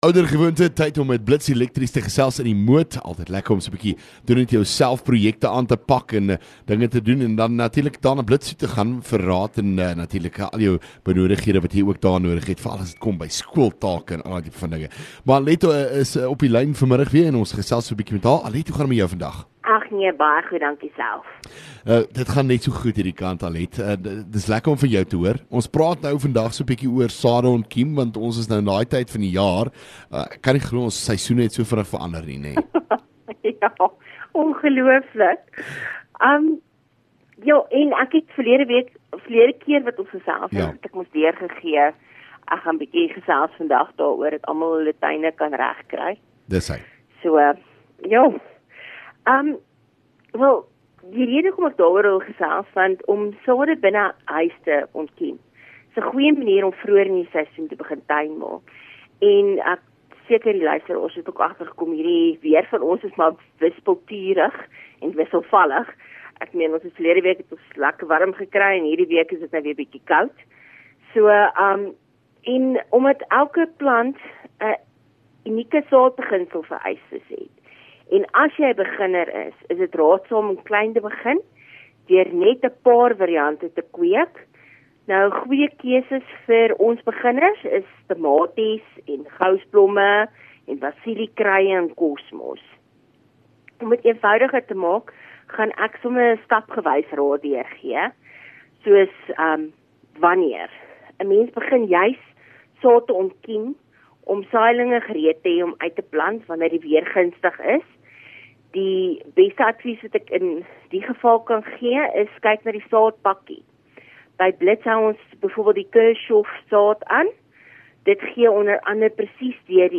LEKKER FM | Onderhoude 28 Aug Blits Elektrisiëns